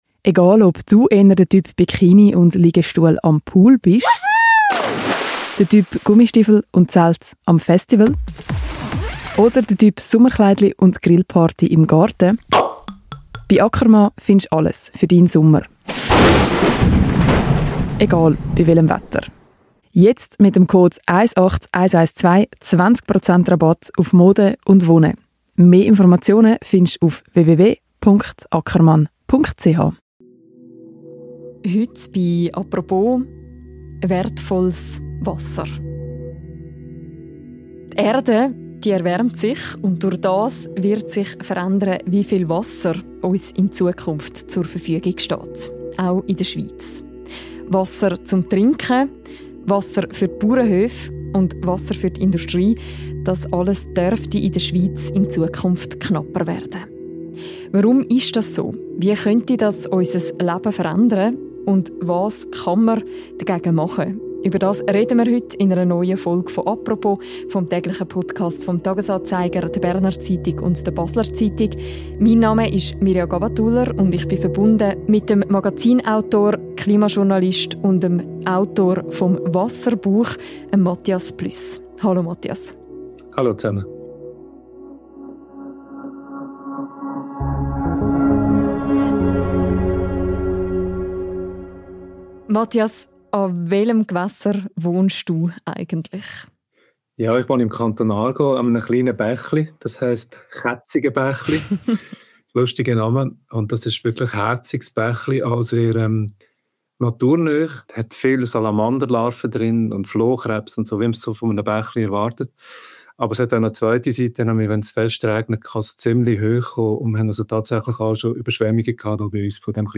Jetzt ist er zu Gast in einer neuen Folge des täglichen Podcasts «Apropos» und erklärt, warum uns das Wasser ausgeht, obwohl es trotz Klimawandel nicht weniger regnet.